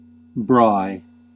Ääntäminen
Synonyymit barbecue Ääntäminen South Africa: IPA : /bɹɑe/ UK : IPA : /bɹɑːɪ/ Haettu sana löytyi näillä lähdekielillä: englanti Käännöksiä ei löytynyt valitulle kohdekielelle.